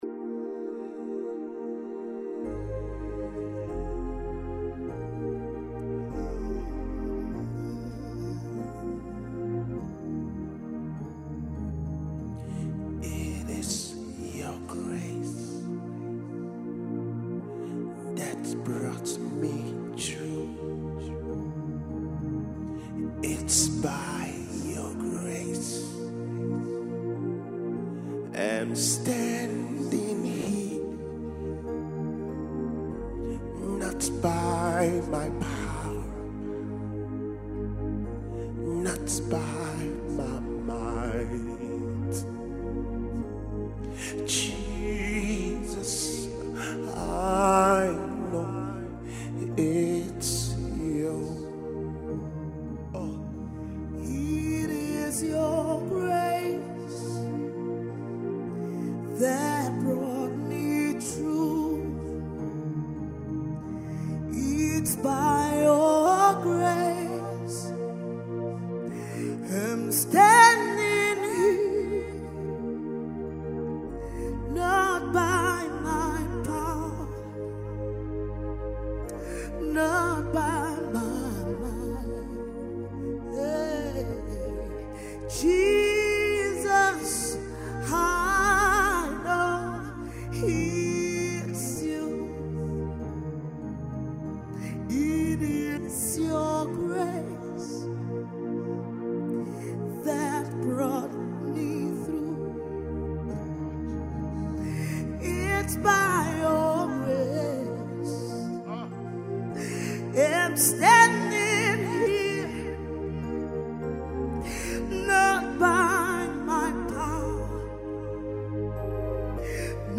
unrelenting indigenous Nigerian Gospel music group